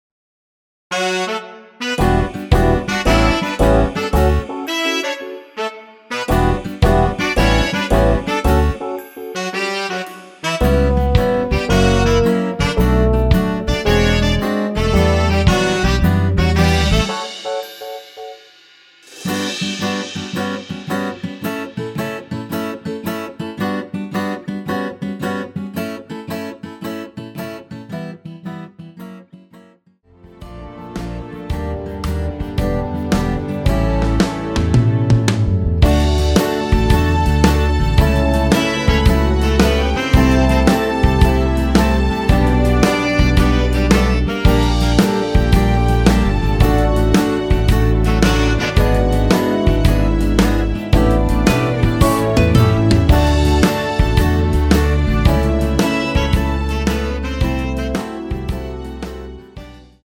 여성분이 부르실만한 축가로 좋은곡
앞부분30초, 뒷부분30초씩 편집해서 올려 드리고 있습니다.
중간에 음이 끈어지고 다시 나오는 이유는